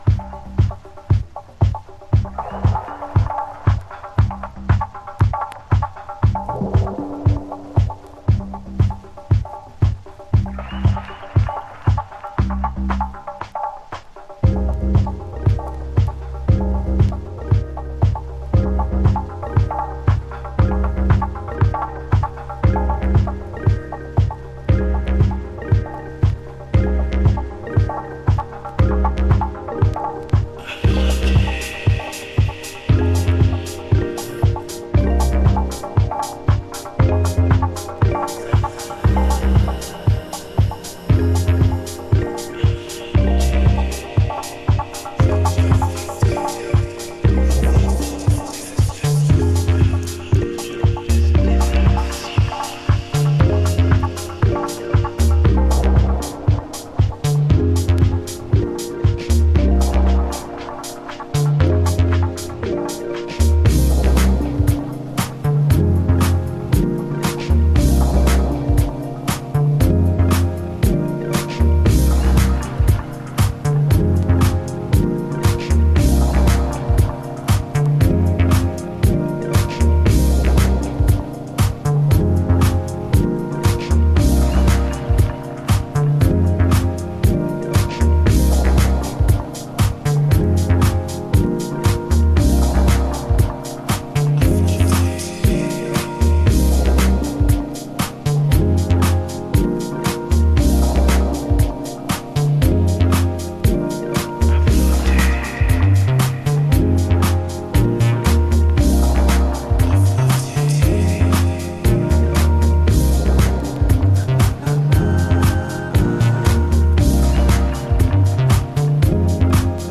House / Techno
いつもより少しスローダウンしたブギー・グルーヴに煌びやかな音響のデイドリーミング・ハウス。
Underwater Vocals